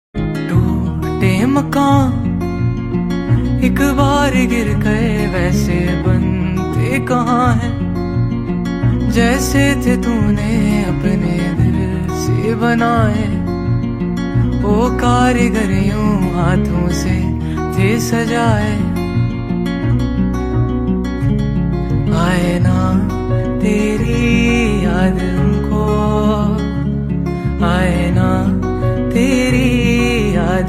soulful track